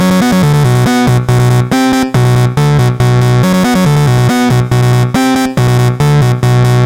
复古技术贝斯实验11
Tag: 140 bpm Techno Loops Bass Synth Loops 1.15 MB wav Key : Unknown